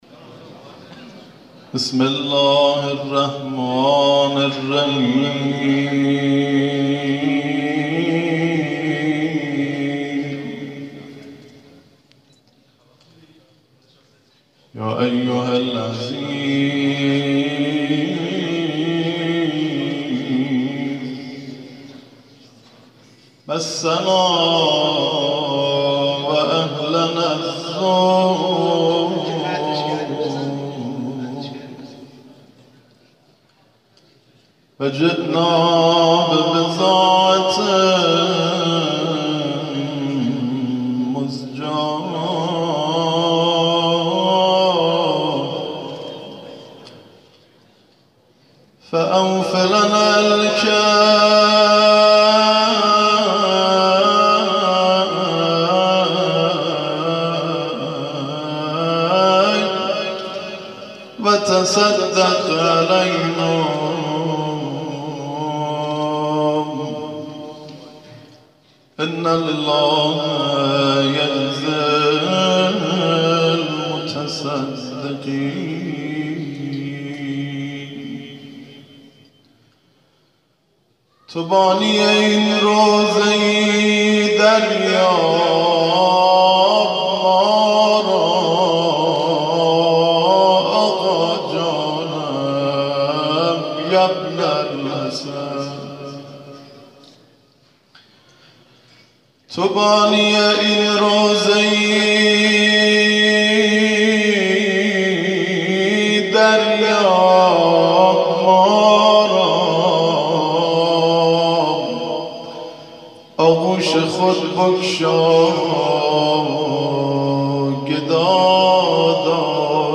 گروه جلسات و محافل: مراسم احیای همنوایی با تلاوت قرآن سالار شهیدان در شب عاشورا ، سه شنبه 20مهرماه مصادف باشب عاشورا همچون سالهای گذشته باحضور اساتید و قاریان بین المللی و ممتاز و قرآنیان از مناطق مختلف کشور، در مسجد جامع المهدی(عج) شهرری، از ساعت ۲۳ الی اذان صبح برگزار ‌شد.